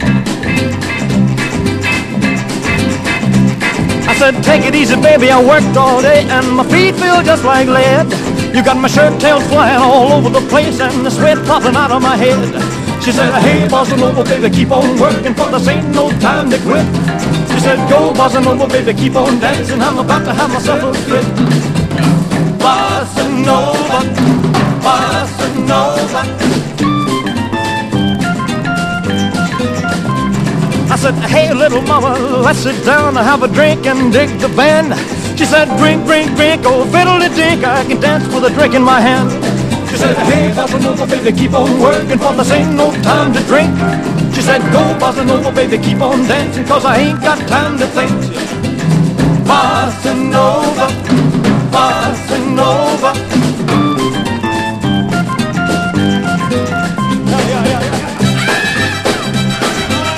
EASY LISTENING / VOCAL / CHORUS
楽しくて美しいコーラス人気作！
こちらは全編に美しいコーラスが入った作品。